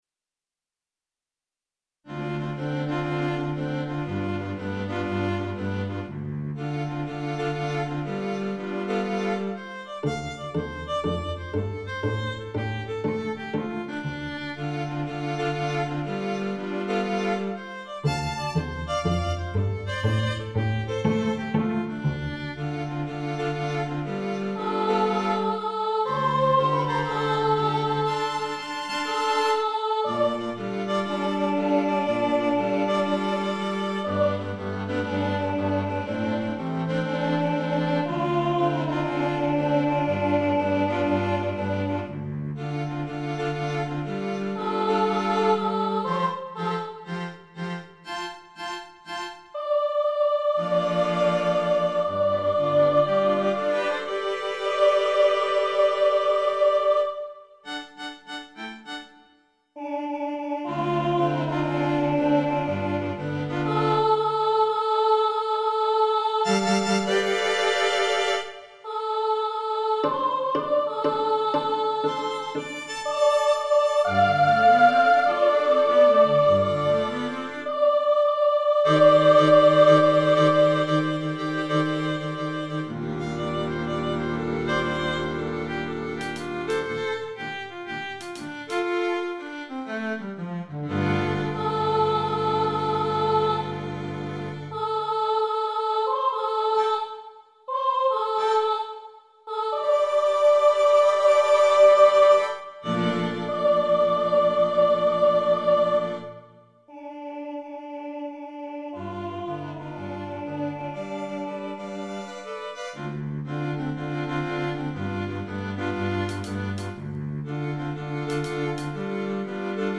MIDI demo
(strings)
This song uses a swing rhythm almost throughout.